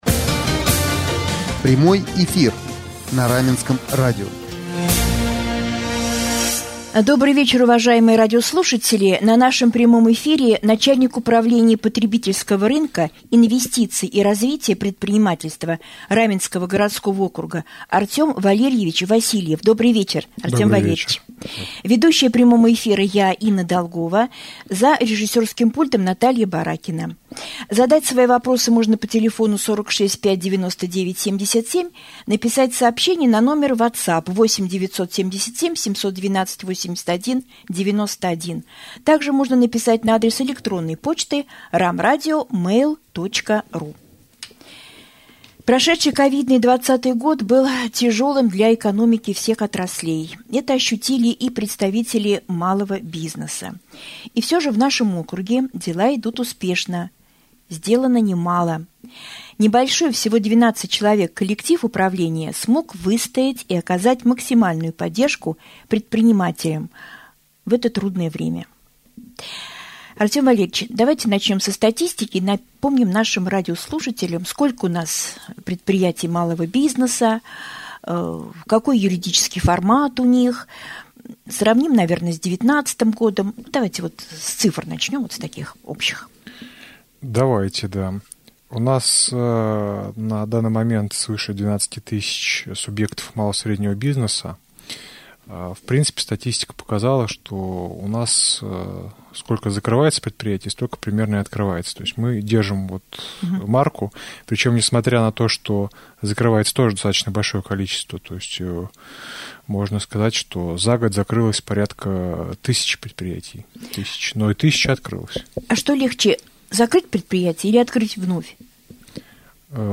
В прямом эфире на Раменском радио побывал Артем Васильев, начальник Управления потребительского рынка, инвестиций и развития предпринимательства администрации Раменского городского округа.